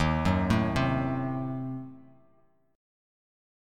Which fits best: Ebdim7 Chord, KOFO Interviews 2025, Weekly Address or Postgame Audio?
Ebdim7 Chord